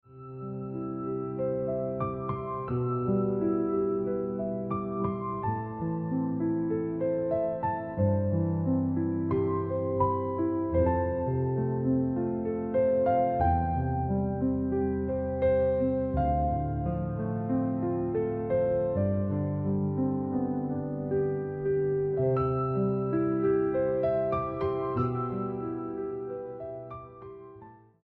Mornings made better with chill cafe vibes + soft music. Perfect for slow starts, journaling, or mindful coffee time.